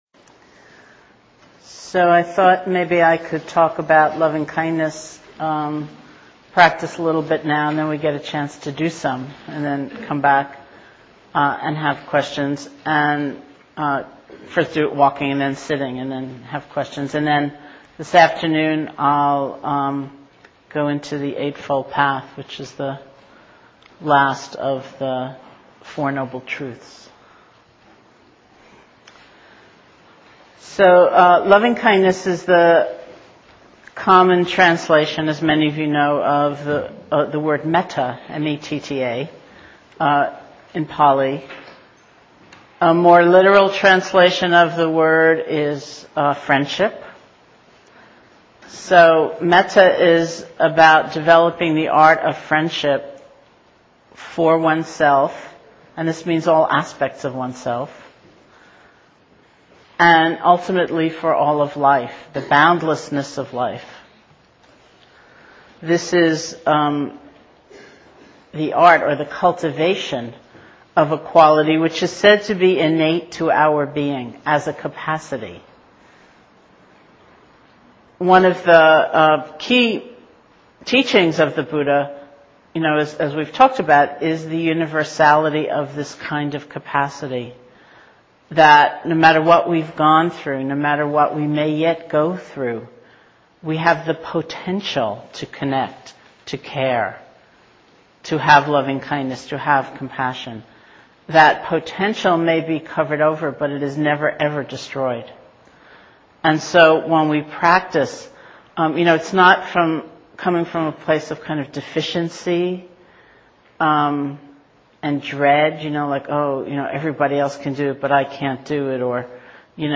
Teachers: Sharon Salzberg